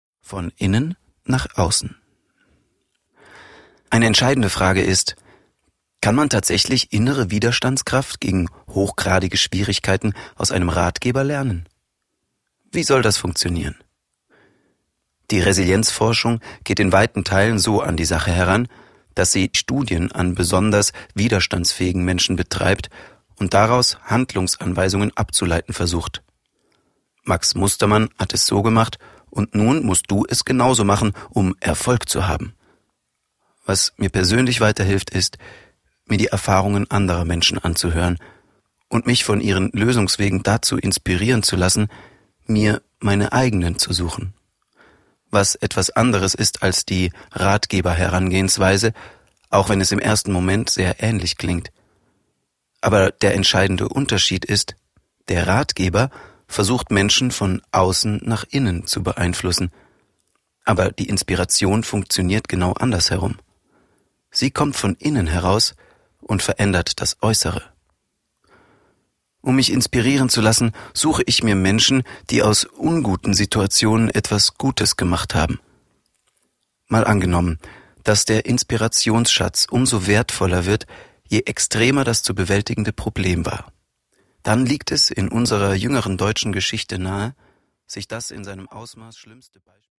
StehaufMensch! - Hörbuch
Unterstützt von dem bekannten Hirnforscher Gerald Hüther begibt sich Samuel Koch auf die Suche: Was trägt uns wirklich durch Krisen? Kann man Resilienz lernen?Das Hörbuch in der ungekürzten Buchfassung wird von Samuel Koch selbst gelesen und inspiriert dazu, die eigene "Stehaufkraft